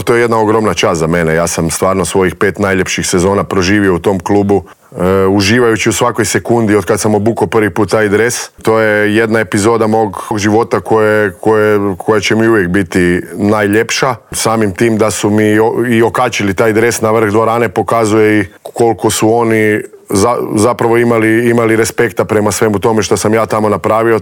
Iz godine u godinu se nadamo da će nam taj naš sport krenuti uzlaznom putanjom, a na čelo kao novi sportski direktor Hrvatskog košarkaškog saveza došao je tek umirovljeni igrač, Krunoslav Simon, koji je bio gost Intervjua tjedna Media servisa.